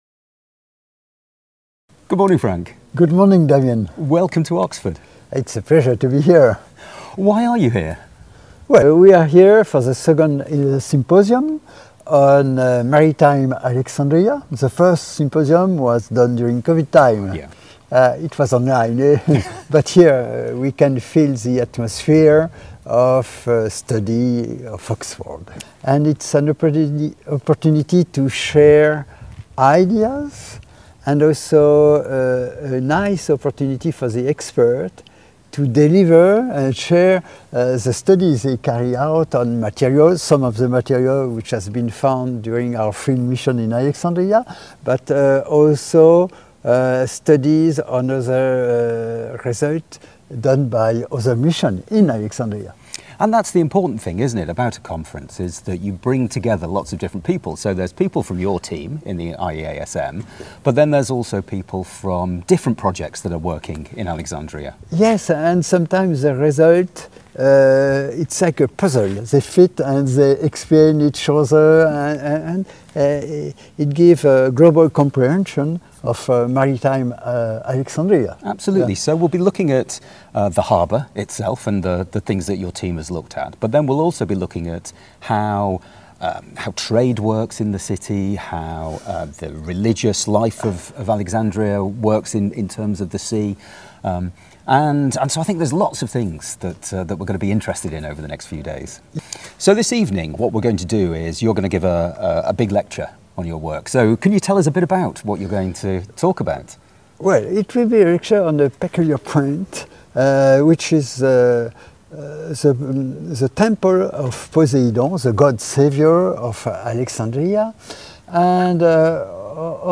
In the picturesque courtyard of Harris Manchester College, they talk about the latest results of the excavations and detailed research on two temples in the sunken royal quarter of the ancient Portus Magnus of Alexandria: the temple to Poseidon and the temple to Isis.